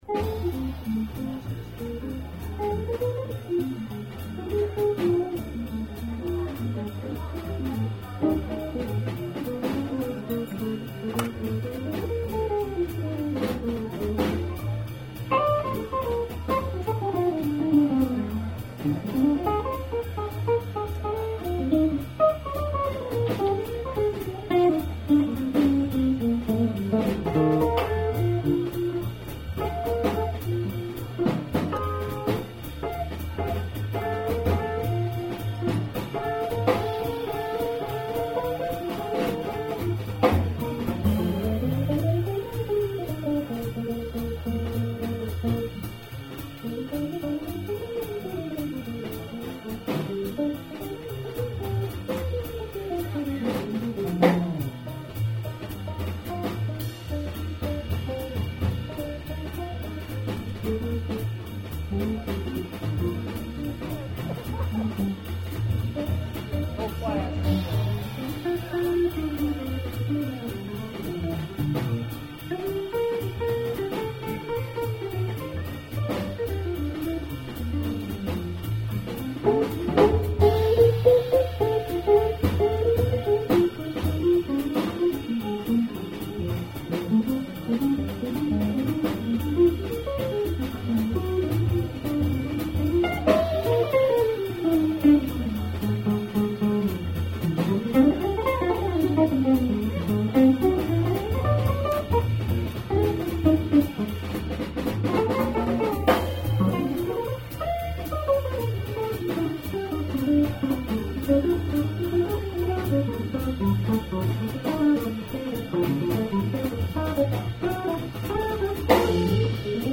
Jazz Guitar Night at the Iron Post - 6-3-2007
At the end, all the guitarists got up on the stage and jammed on a blues
bass
drums